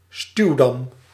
Ääntäminen
US UK : IPA : /ˈbæɹɑːʒ/ US : IPA : /bəˈɹɑːʒ/